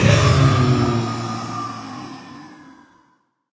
sounds / mob / blaze / death.ogg
death.ogg